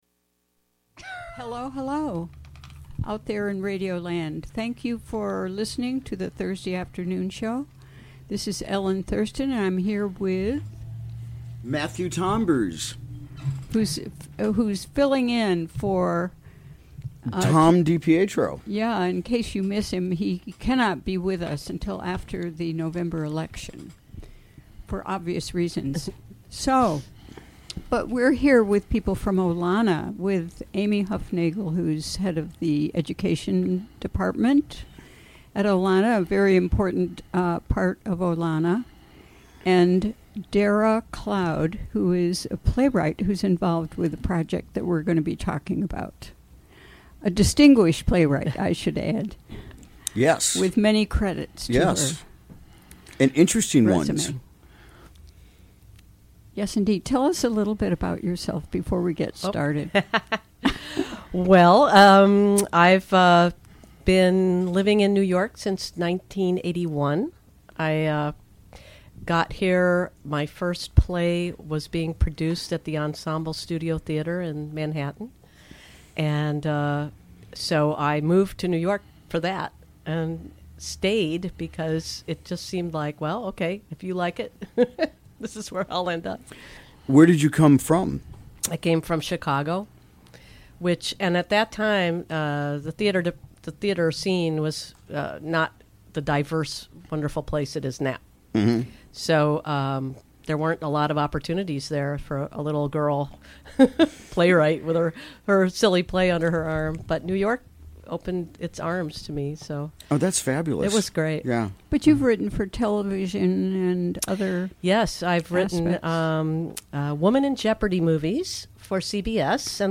Recorded during the WGXC Afternoon Show on September 14, 2017.